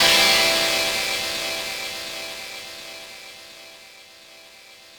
ChordAm7.wav